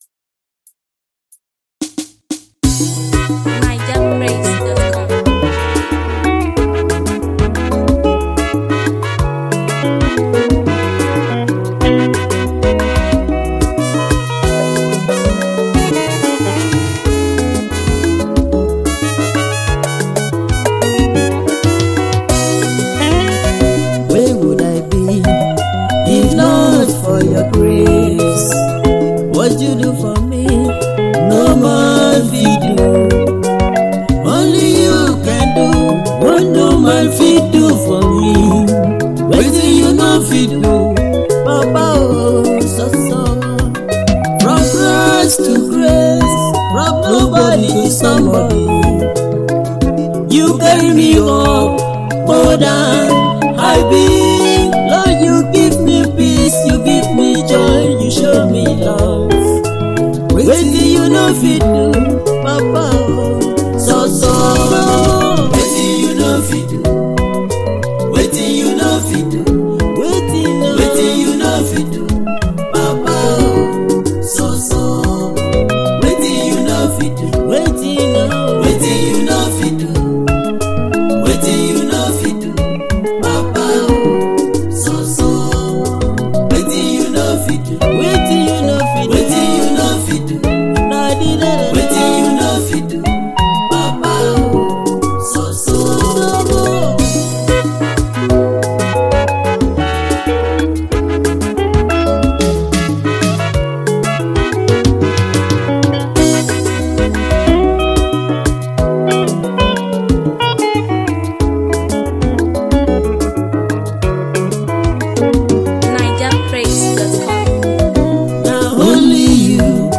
African praiseAfro beatmusic
a Nigeria gospel singer